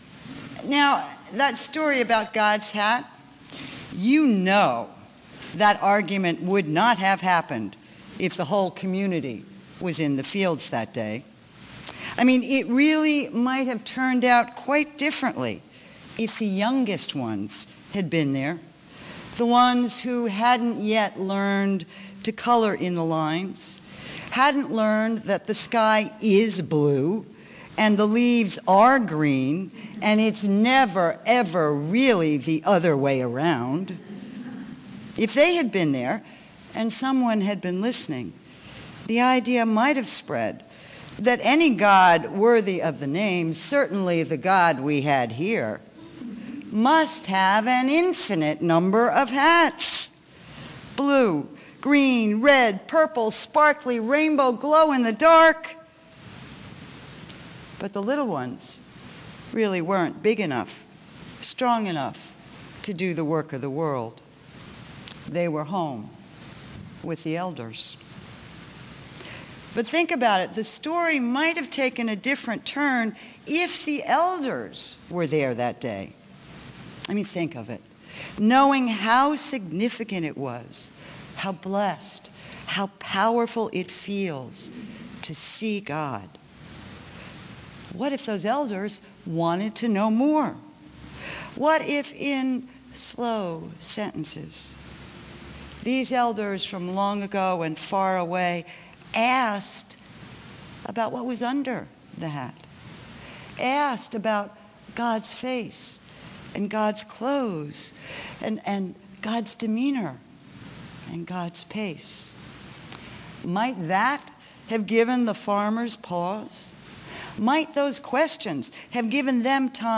by | Sep 15, 2013 | Recorded Sermons | 0 comments